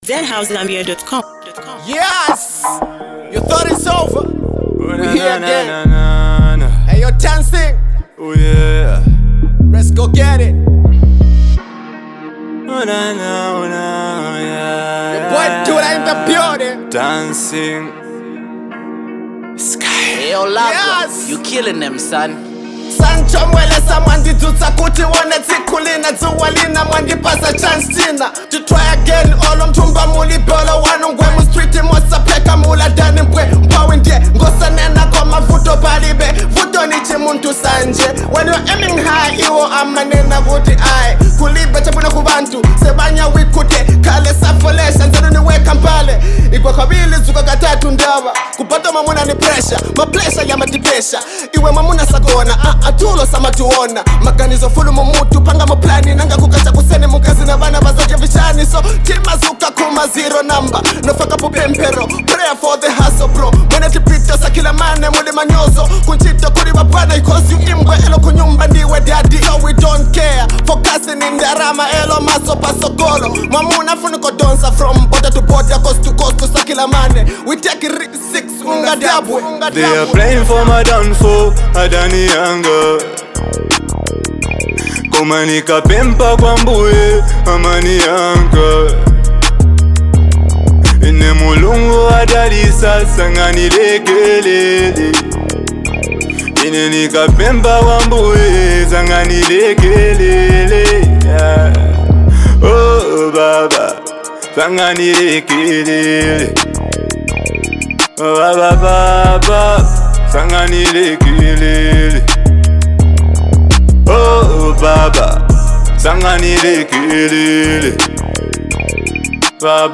rapper
street banger that’s full of energy and flavor.
vibey anthem for the hustlers and party lovers.